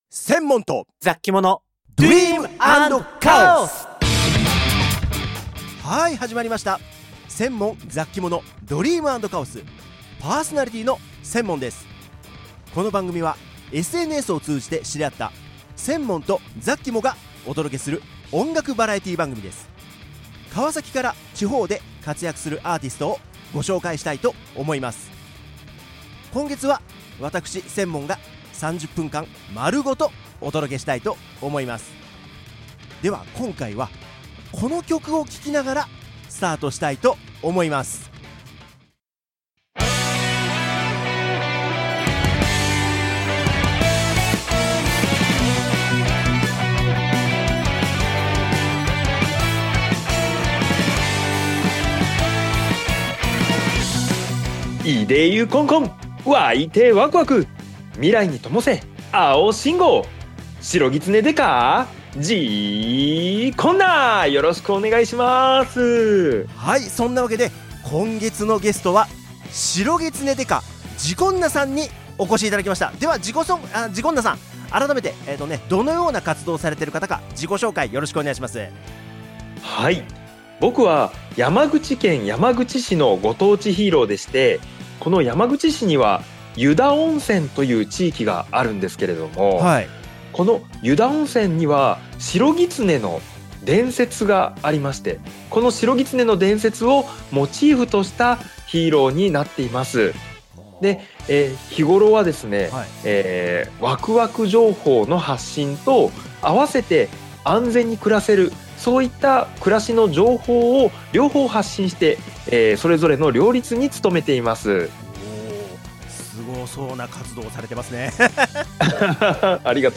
少しテンポを落としたアレンジが、大人のクリスマスって感じです（＾＾） 想ワレは奈良県を拠点に活動している女性二人組ユニット。